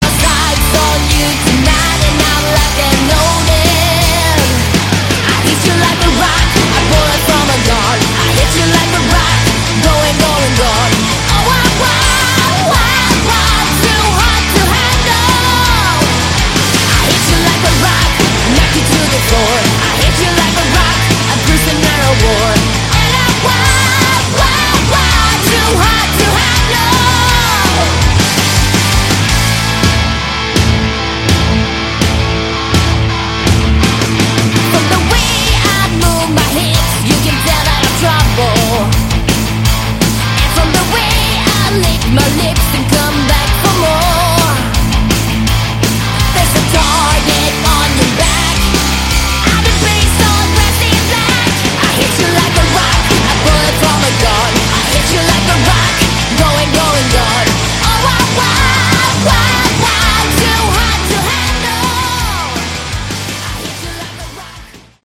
Category: Hard Rock
lead vocals
lead guitar, vocals
bass, vocals
drums